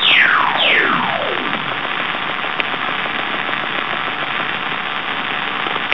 Listen to an AUDIBLE Trans Ionospheric Pulse Pair (TIPP) (event number e10305) Array of Low-Energy X-ray Imaging Sensors [ALEXIS]